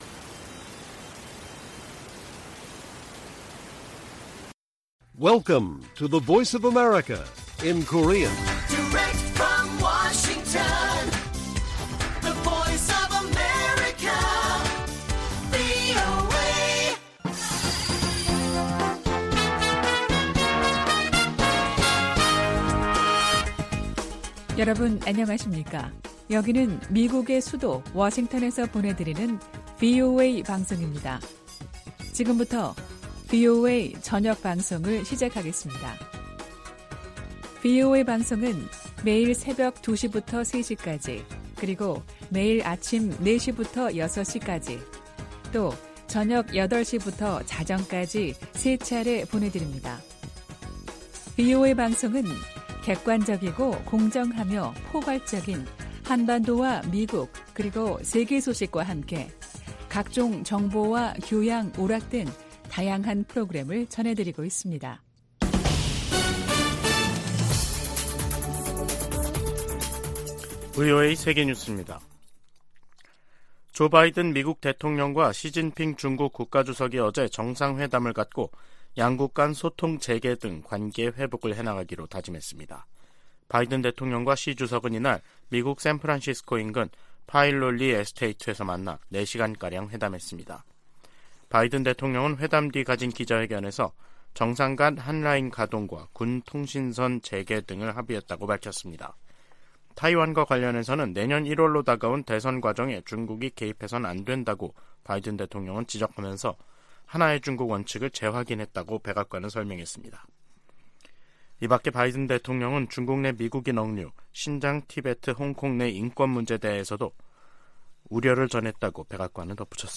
VOA 한국어 간판 뉴스 프로그램 '뉴스 투데이', 2023년 11월 16일 1부 방송입니다. 조 바이든 미국 대통령이 15일 시진핑 중국 국가 주석과의 회담에서 한반도의 완전한 비핵화에 대한 미국의 의지를 다시 한번 강조했습니다. 유엔총회 제3위원회가 북한의 인권 유린을 규탄하는 북한인권결의안을 19년 연속 채택했습니다. 북한과 러시아는 정상회담 후속 조치로 경제공동위원회를 열고 무역과 과학기술 등 협력 방안에 합의했습니다.